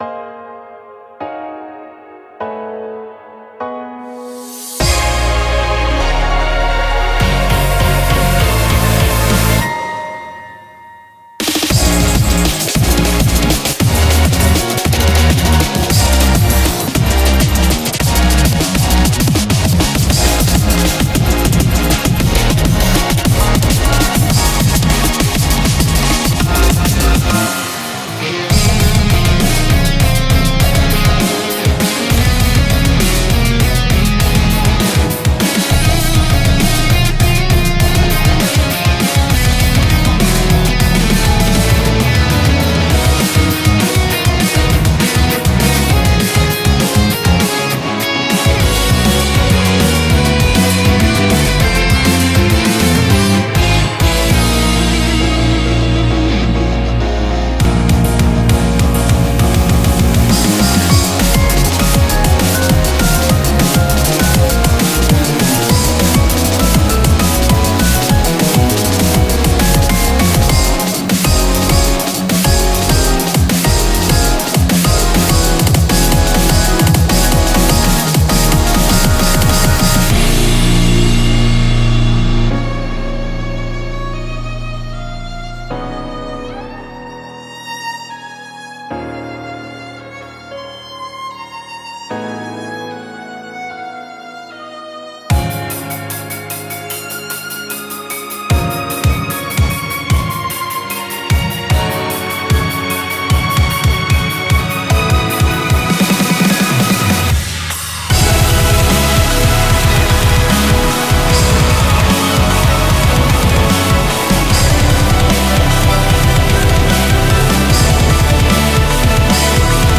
BPM75-200
Audio QualityMusic Cut
A SONG WITH TIME SIGNATURES.